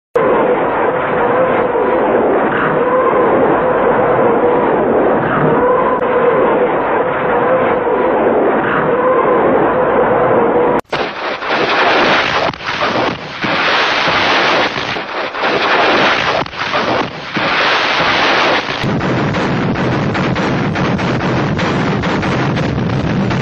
На этой странице собраны исторические записи звуков залпов «Катюши» — легендарной реактивной системы, ставшей символом победы в Великой Отечественной войне.
Звук винтажной записи стрельбы из Катюш